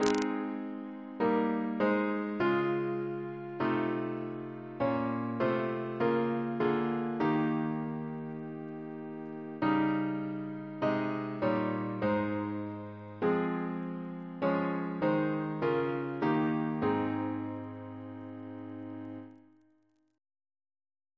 Double chant in A♭ Composer: Josiah Booth (1828-1879)